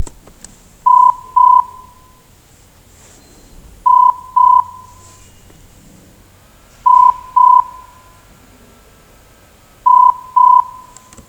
【NAKAYO（ナカヨ）ST101A 内線着信音サンプル】
■内線着信音　A